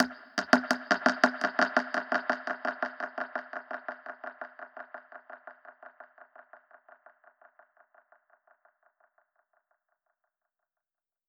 Index of /musicradar/dub-percussion-samples/85bpm
DPFX_PercHit_B_85-09.wav